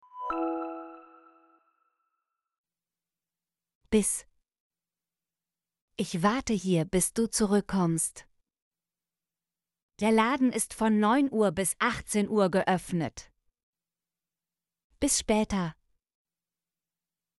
bis - Example Sentences & Pronunciation, German Frequency List